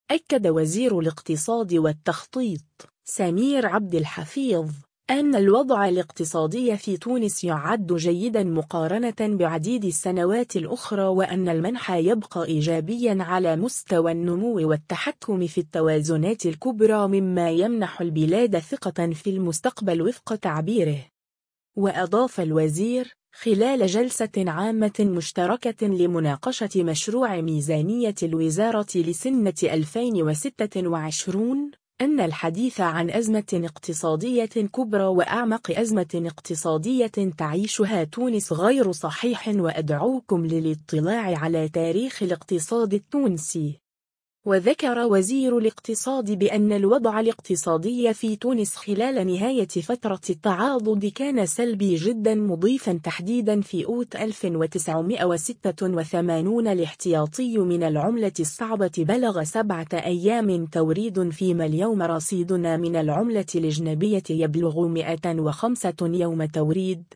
وأضاف الوزير، ، خلال جلسة عامة مشتركة لمناقشة مشروع ميزانية الوزارة لسنة 2026، “أنّ الحديث عن أزمة اقتصادية كبرى وأعمق أزمة اقتصادية تعيشها تونس غير صحيح وأدعوكم للاطلاع على تاريخ الاقتصاد التونسي”.